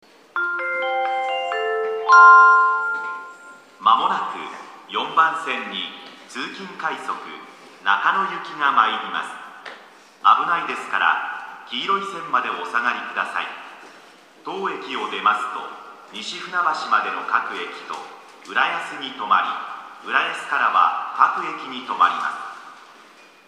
駅放送
2014年3月3日頃より接近メロディ付きの新放送に切り替わりました。